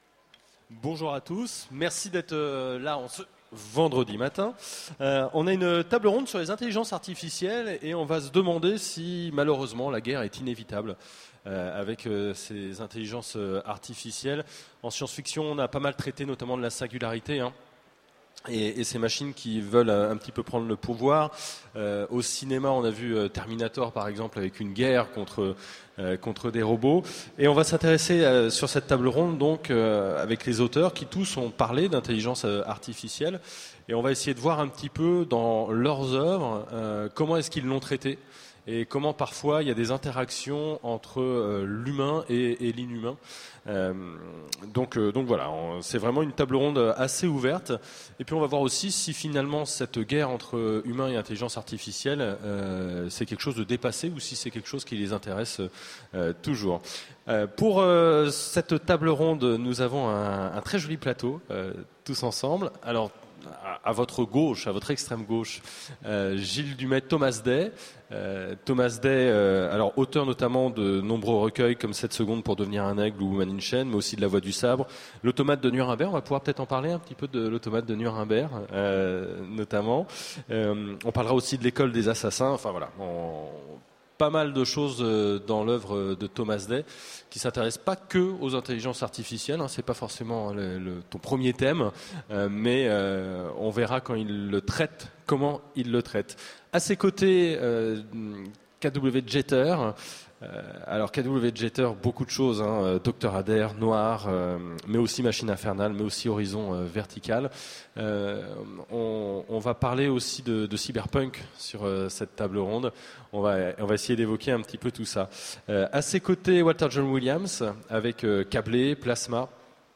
Mots-clés Intelligence Intelligence artificielle Conférence Partager cet article